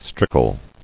[strick·le]